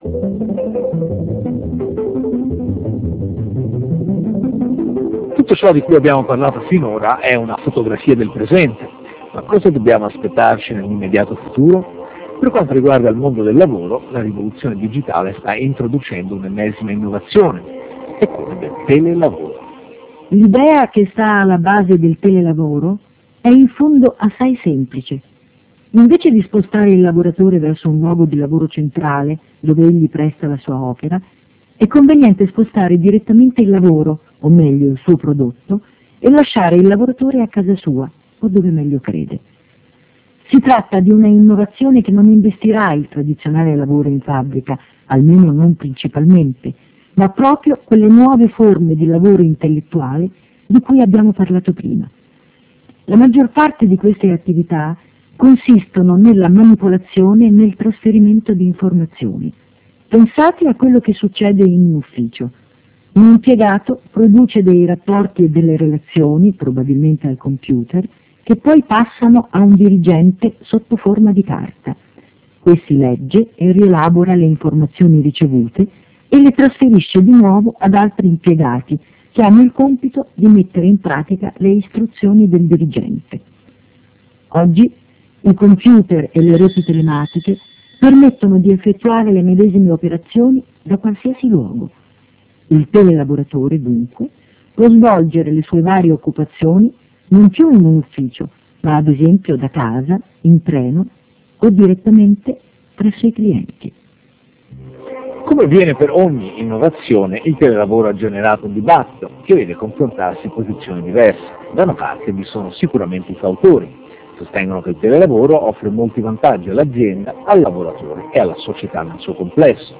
Lezione n. 09